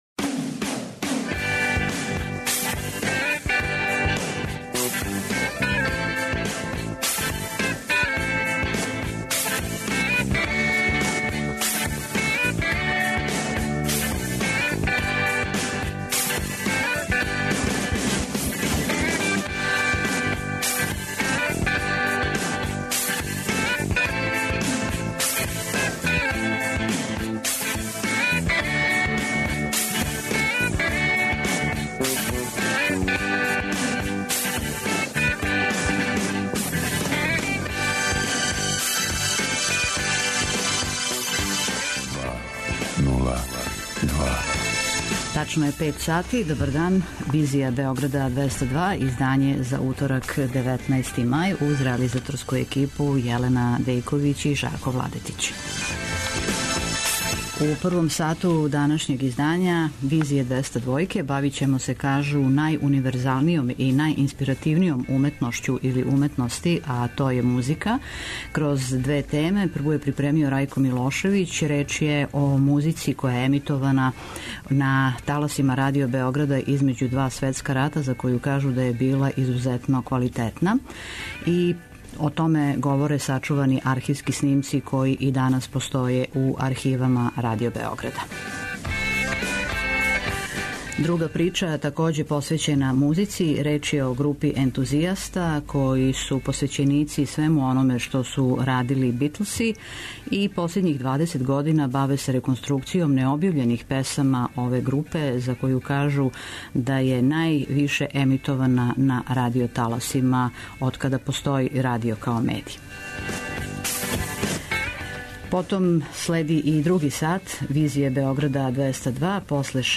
преузми : 55.10 MB Визија Autor: Београд 202 Социо-културолошки магазин, који прати савремене друштвене феномене.